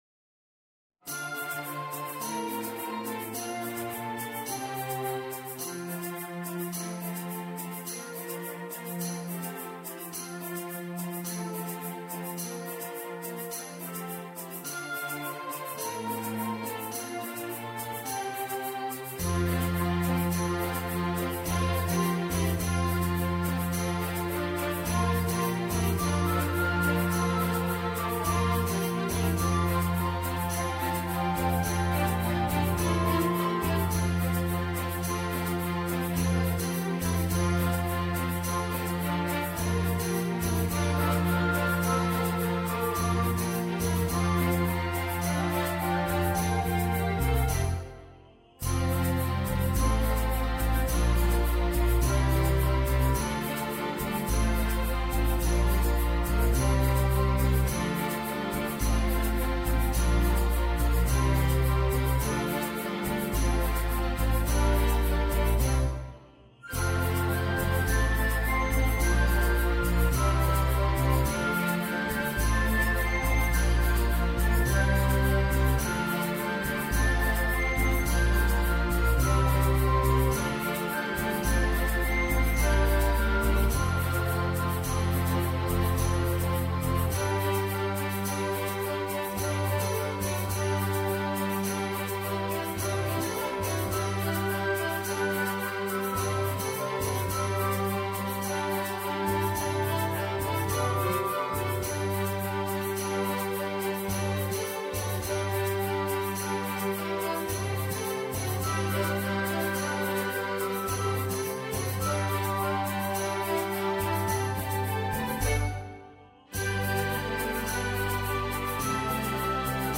Road-Goes-On-Soprano-1.mp3